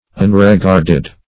unregarded - definition of unregarded - synonyms, pronunciation, spelling from Free Dictionary